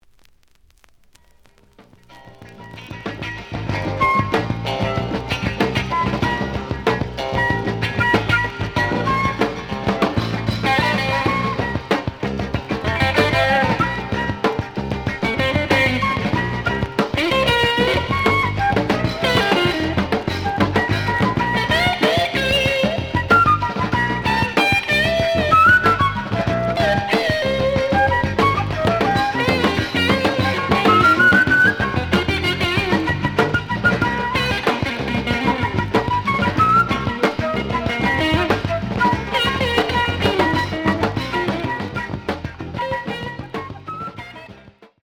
The audio sample is recorded from the actual item.
●Genre: Jazz Funk / Soul Jazz
Slight cloudy on B side.